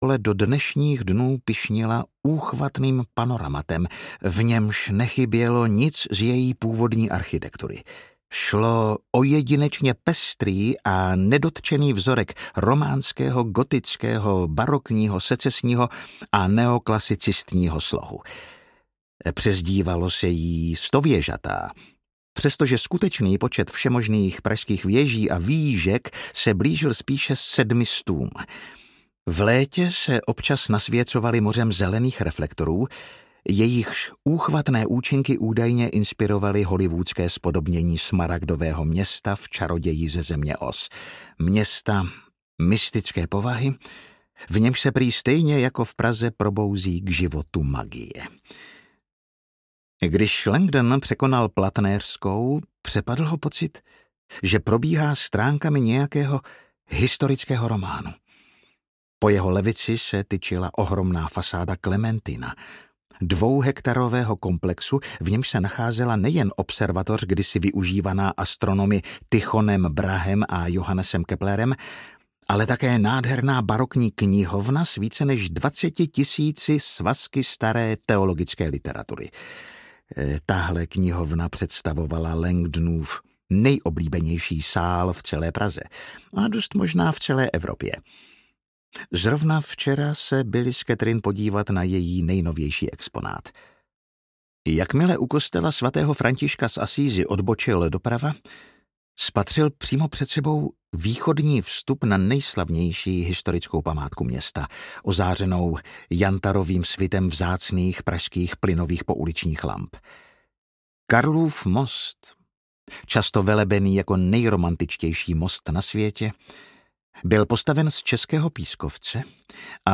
Tajemství všech tajemství audiokniha
Ukázka z knihy
Vyrobilo studio Soundguru.